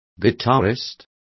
Complete with pronunciation of the translation of guitarist.